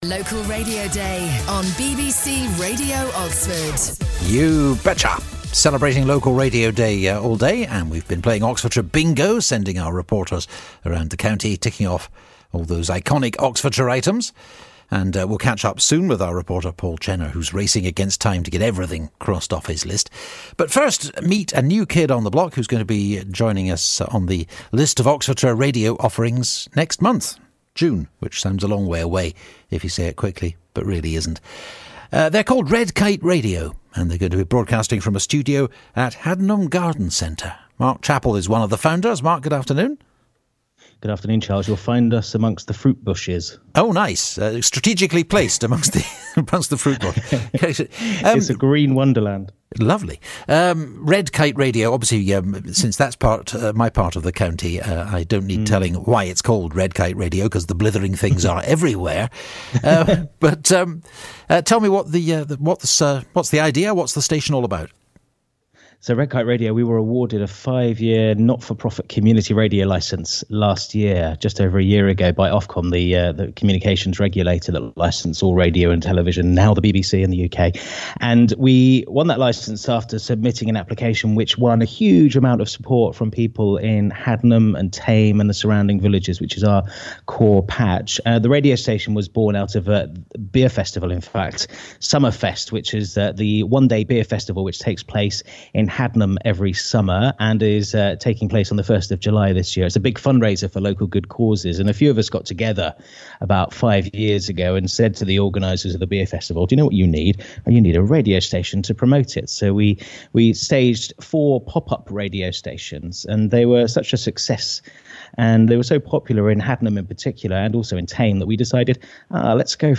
Red Kite Radio on BBC Radio Oxford, Local Radio Day 26 May 2017